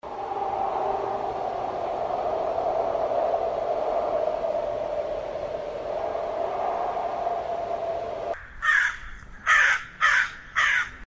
Eerie Wind, Crow Caw, & Creaky Tree: Spooky Forest Ambiance
Immerse your audience in a chilling atmosphere with this sound effect featuring strong wind, a distant crow caw, and the unsettling creak of a tree....
eerie-wind-crow-caw-creaky-tree-spooky-forest-ambiance-ea7c31f0.mp3